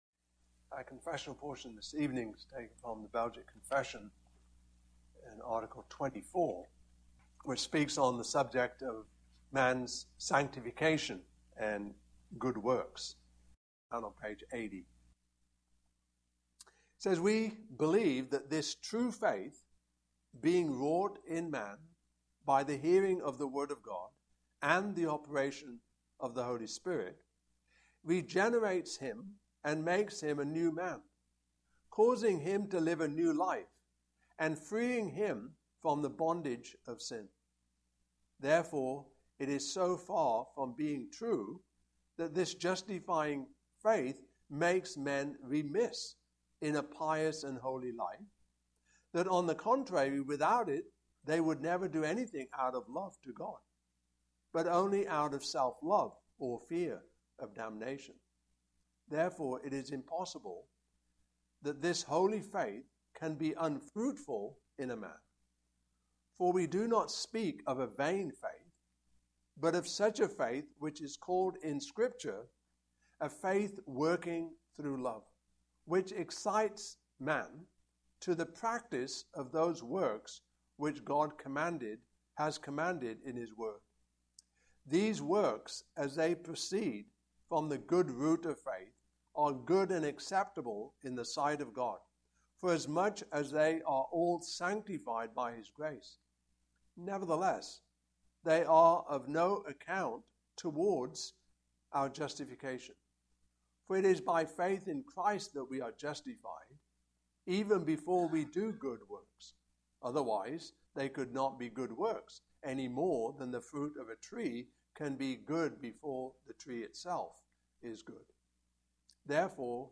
Passage: I Peter 1:13-22 Service Type: Evening Service